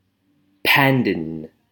Ääntäminen
Ääntäminen US Tuntematon aksentti: IPA : /ˈpændən/ Haettu sana löytyi näillä lähdekielillä: englanti Kieli Käännökset ranska vacoa Määritelmät Substantiivi (countable) A screw pine , screw palms ( Pandanus spp.).